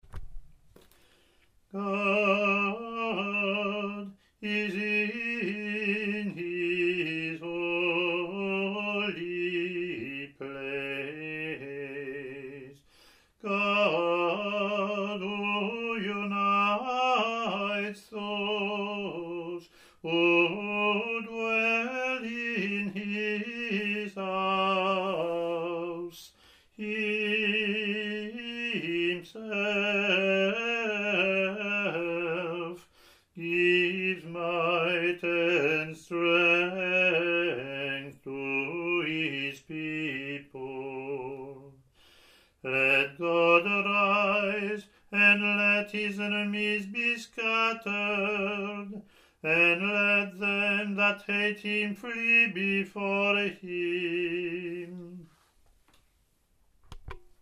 English antiphon – English verse , Latin antiphon and verses )